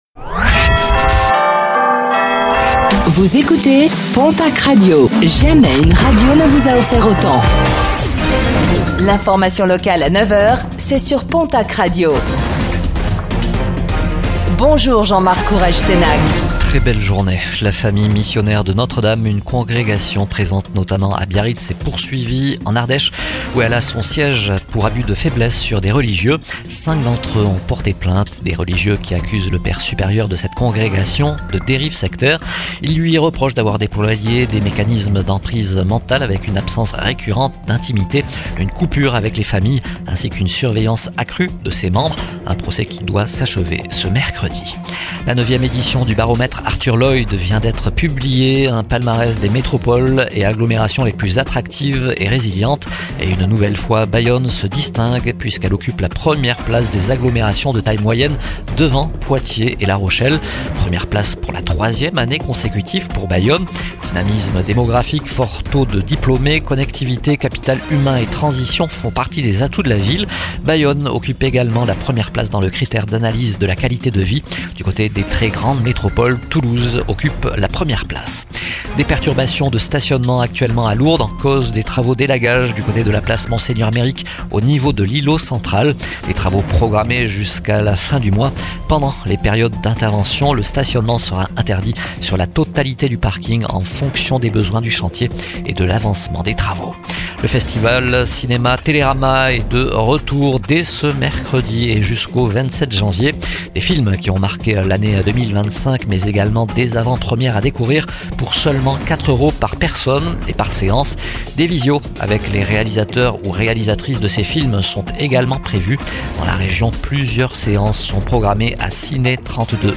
09:05 Écouter le podcast Télécharger le podcast Réécoutez le flash d'information locale de ce mercredi 21 janvier 2026